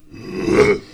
spawners_mobs_uruk_hai_attack.2.ogg